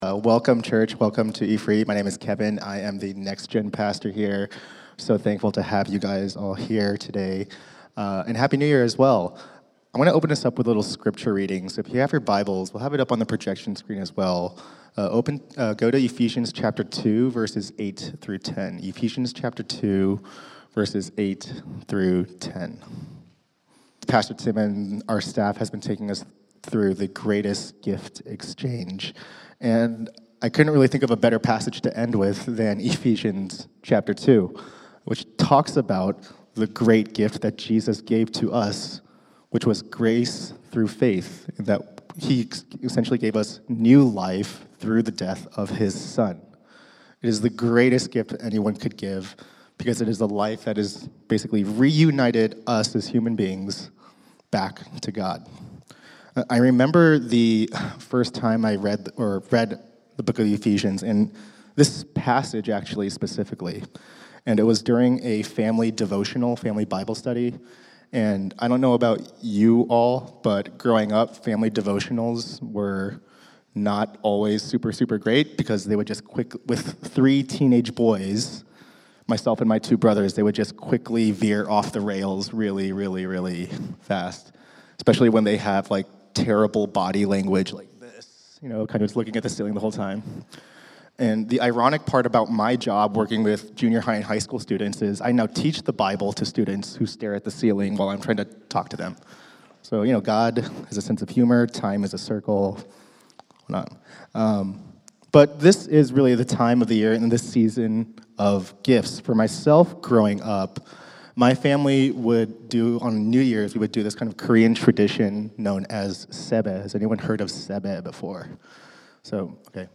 Sunday Sermons
dec-29th-sermon-mp3.mp3